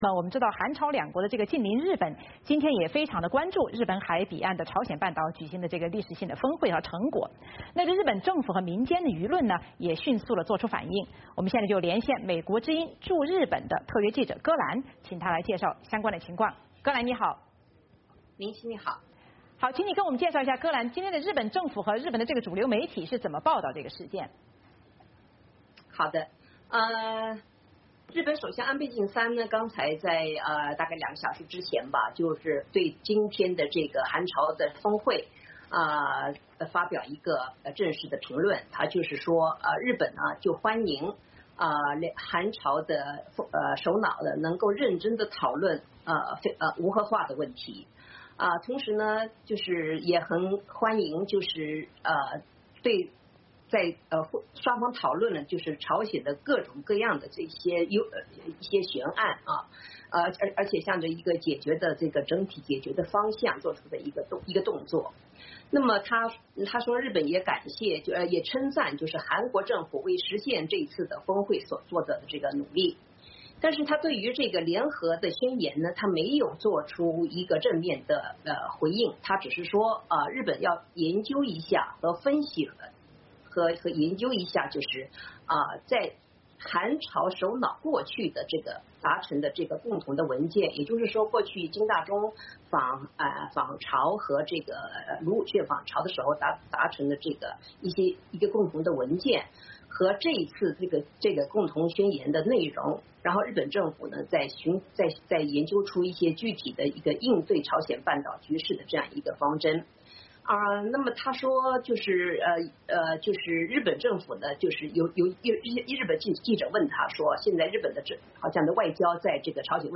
特别报道连线